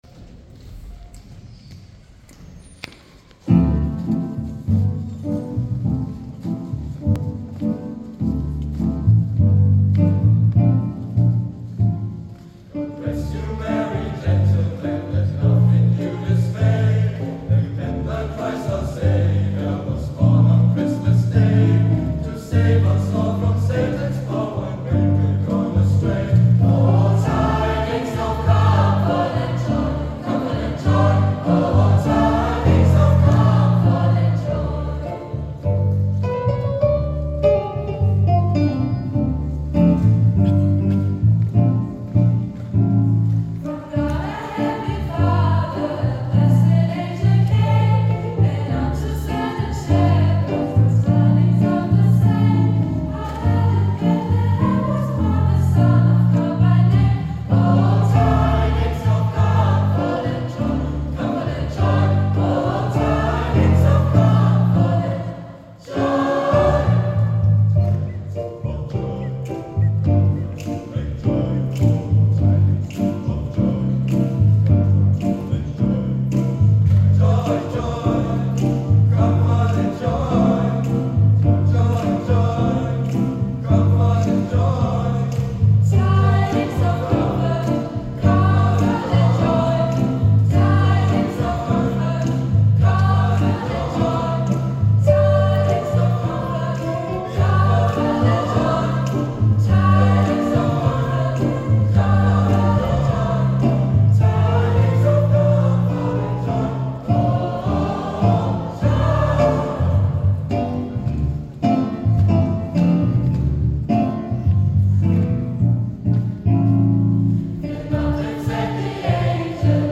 Es singt der Jugendchor „The Golden Owls“, Live-Mitschnitt vom 12.12.2024.